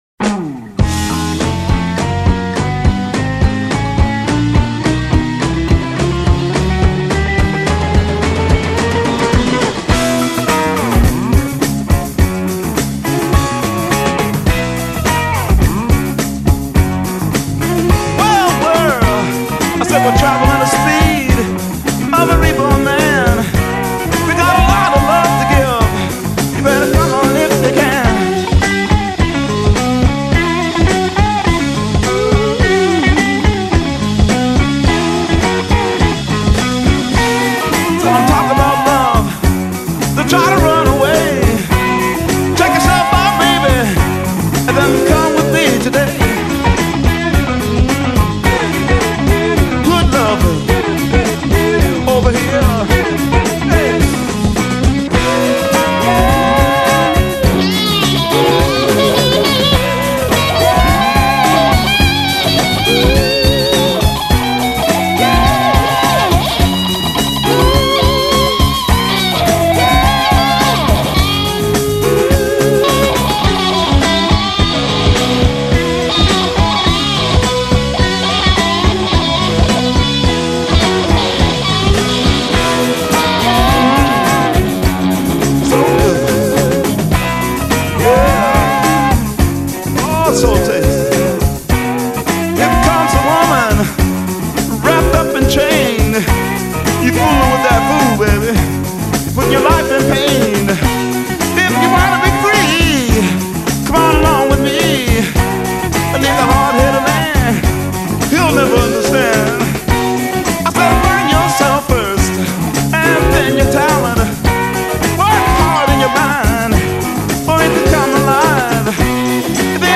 آلبوم راک/سایکدلیک
Psychedelic Rock, Blues Rock